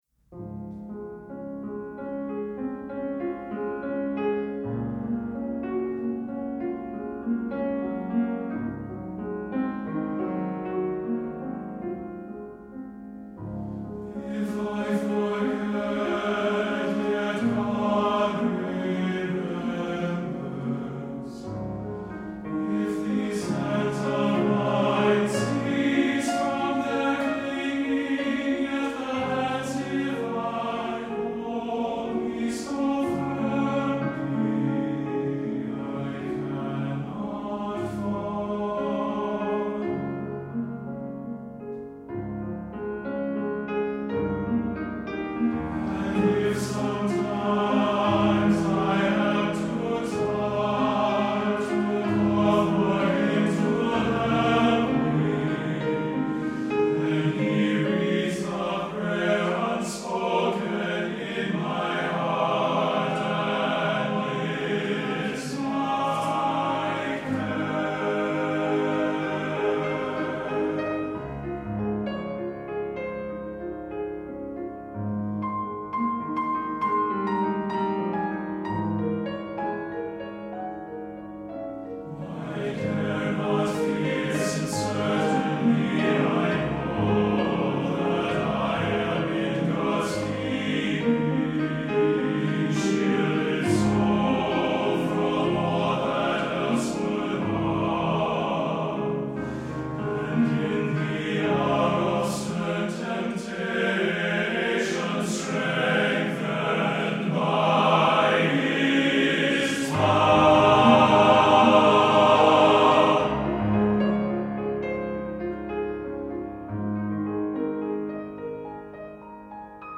Voicing: TTBB and Piano